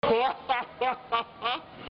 hahaha (slowed)